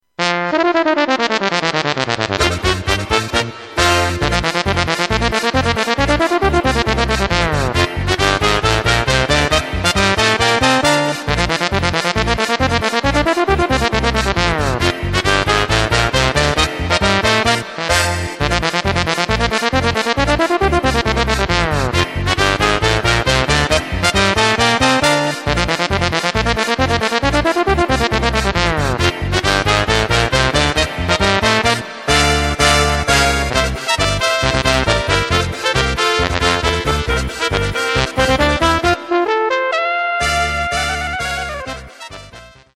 Takt:          2/4
Tempo:         136.00
Tonart:            Bb
Polka für Bariton Solo!